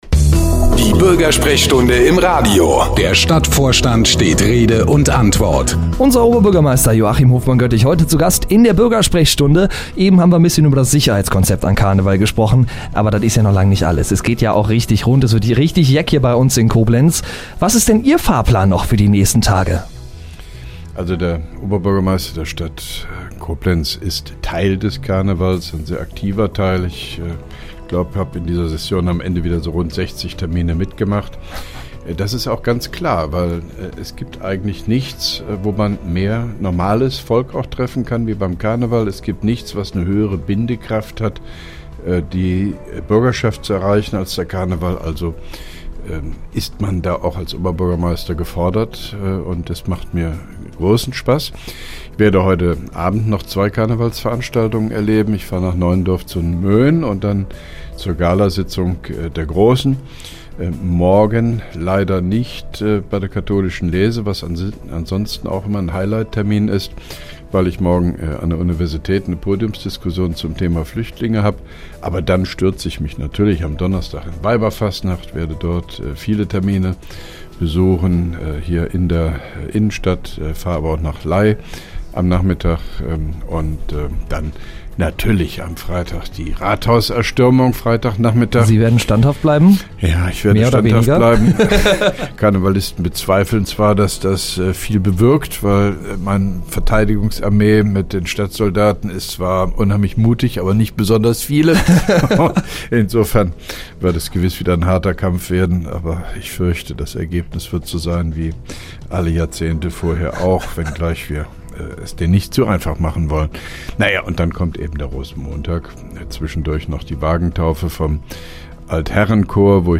Geschrieben in Interviews/Gespräche JoHo, Medien/Presse zu JoHo, Radio-JoHo, RadioBürgersprechstunde OB von joho | Keine Kommentare